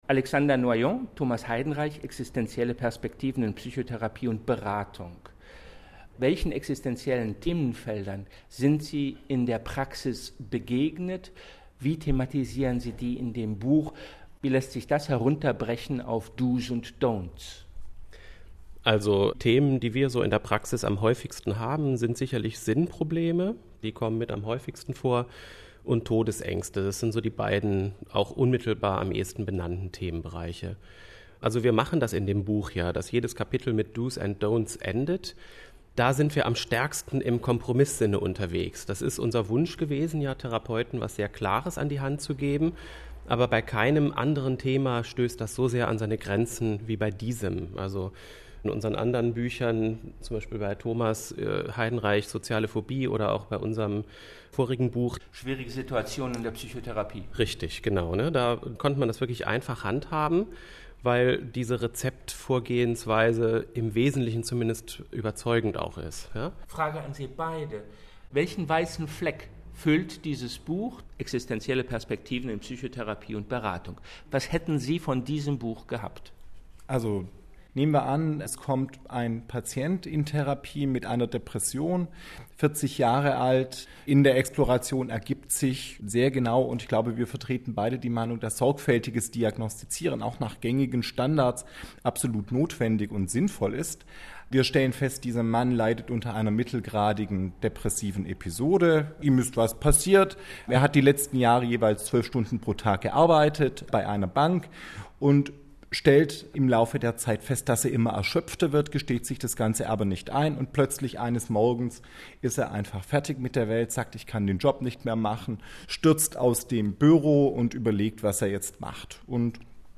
Interview Existenzielle Perspektiven.mp3